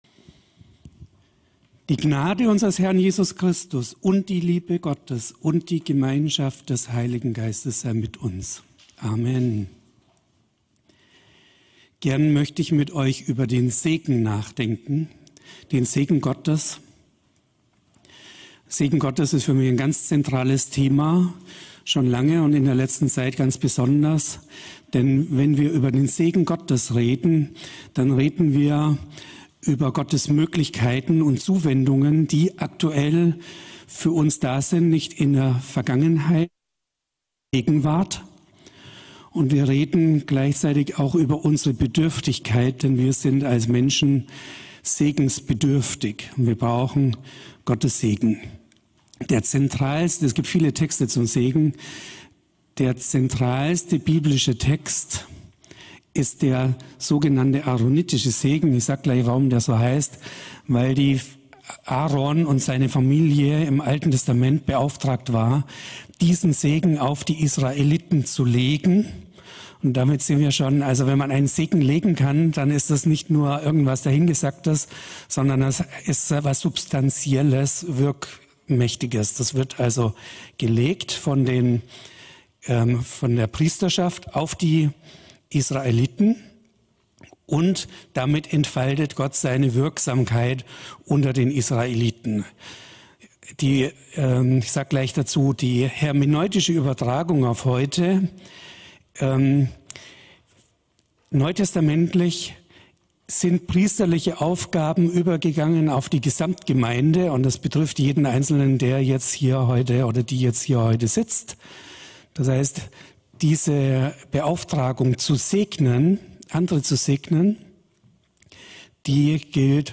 Hier erscheinen meist wöchentlich die Predigten aus dem Sonntags-Gottesdienst des CVJM Stuttgart